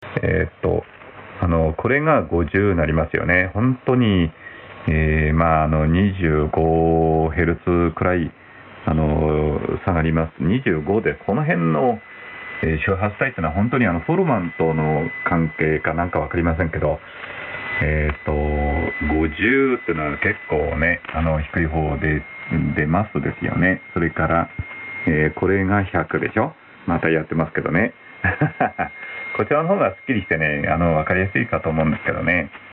Sample Hi‑Fi SSB Audio
Rx:FT DX 9000D / Tx: PSN + TS-850 , Rx band width 4kHz